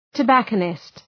Προφορά
{tə’bækənıst}